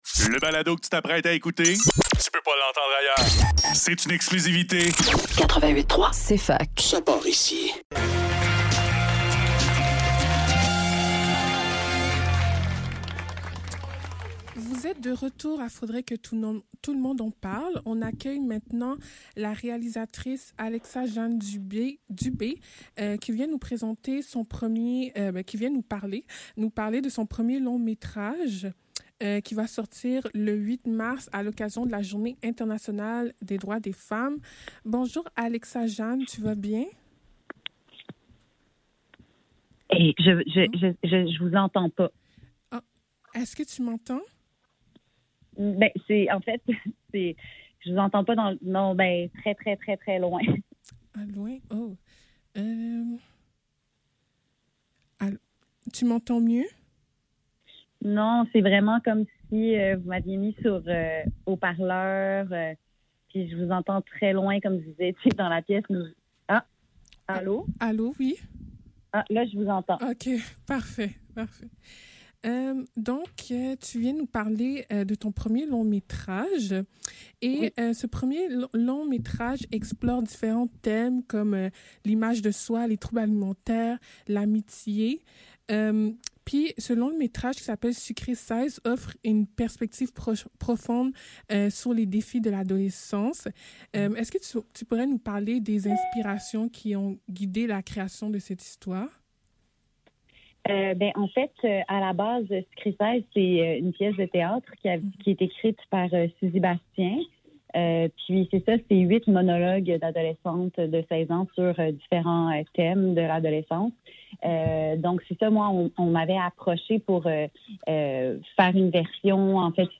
Faudrait que tout l'monde en parle - Entrevue avec Alexa-Jeanne Dubé - 29 février 2024